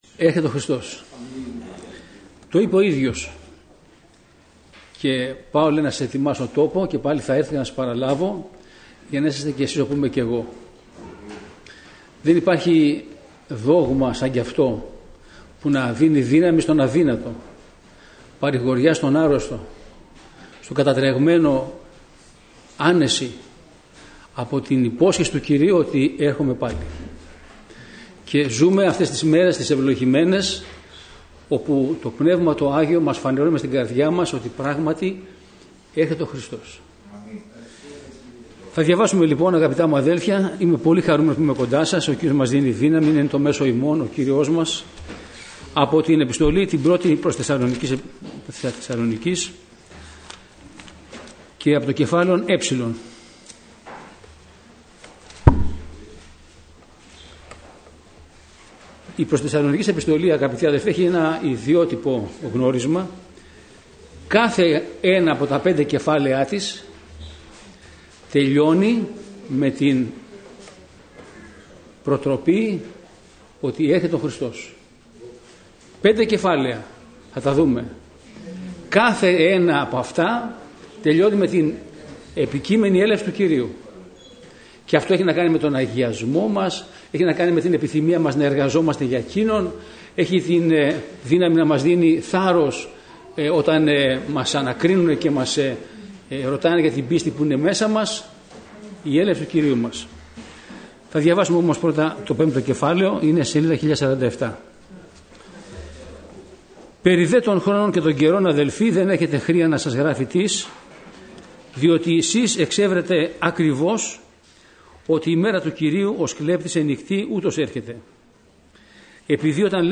Στο Αρχείο Κηρυγμάτων θα βρείτε τα τελευταία Κηρύγματα, Μαθήματα , Μηνύματα Ευαγγελίου που έγιναν στην Ελευθέρα Αποστολική Εκκλησία Πεντηκοστής Αγίας Παρασκευής
Σειρά: Κηρύγματα